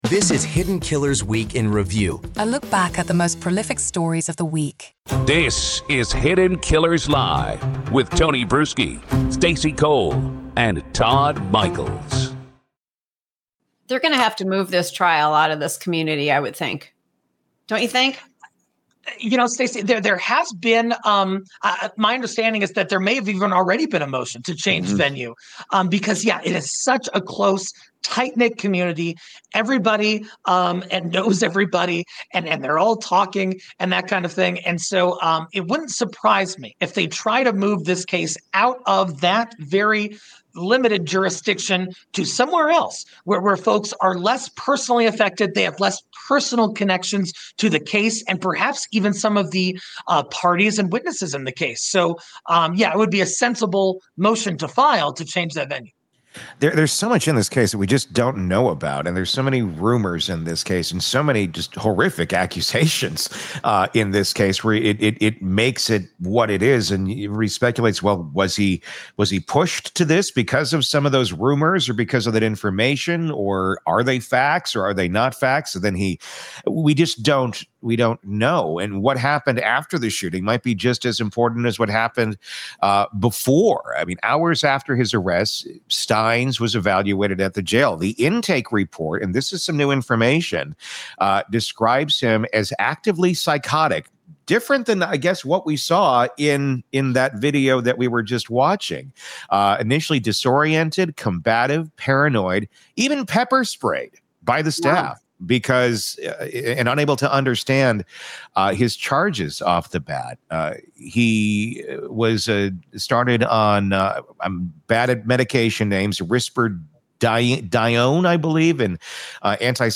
Each episode navigates through multiple stories, illuminating their details with factual reporting, expert commentary, and engaging conversation.
Expect thoughtful analysis, informed opinions, and thought-provoking discussions beyond the 24-hour news cycle.